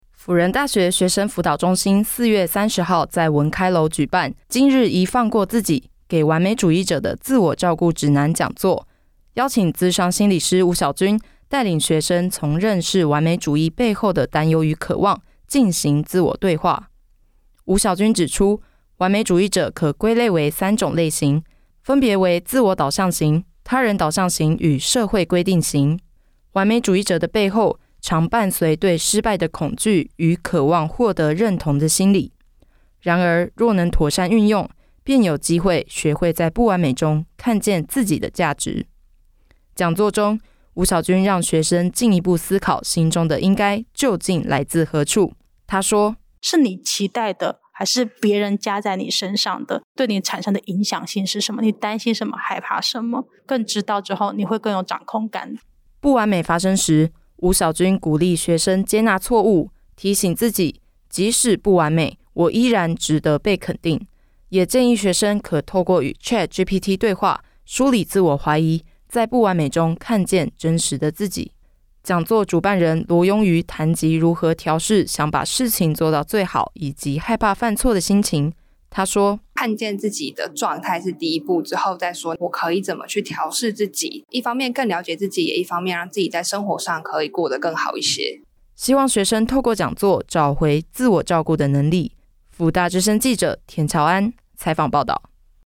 輔大之聲記者
採訪報導